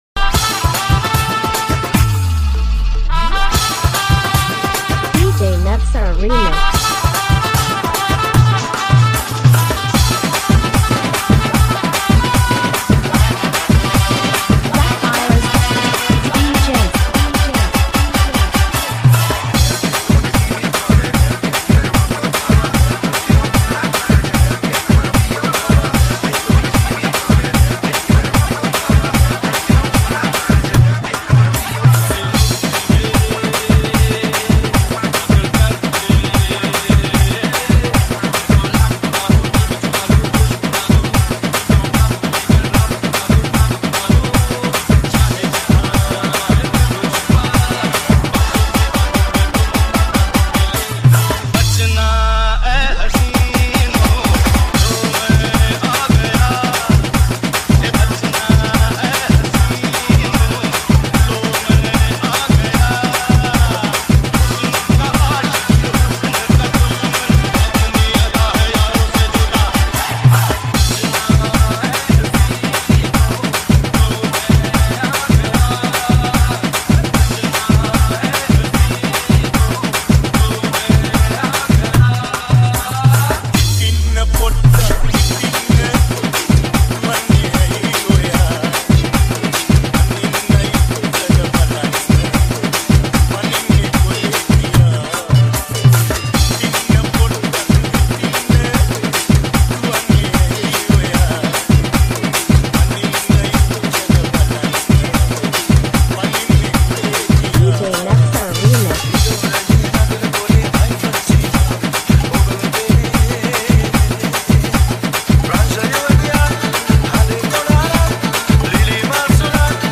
Sawanatha Remix New Song